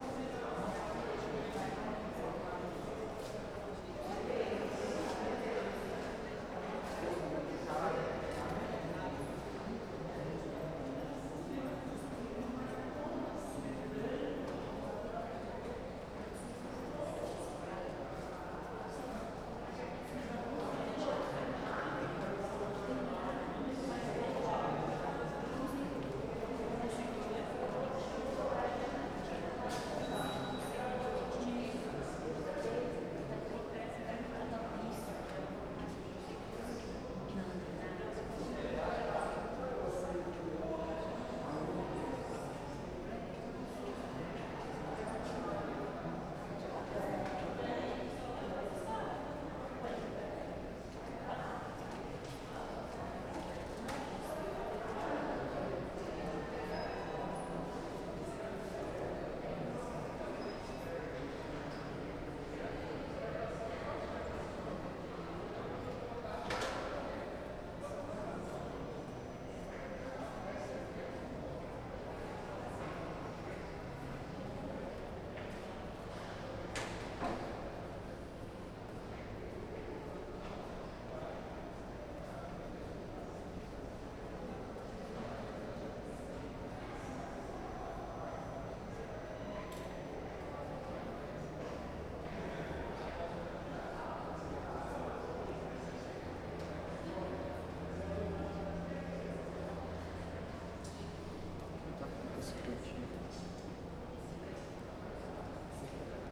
CSC-04-129-LE - Ambiencia de frente para elevador do senado com pessoas.wav